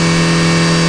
1 channel
MOTOR8.mp3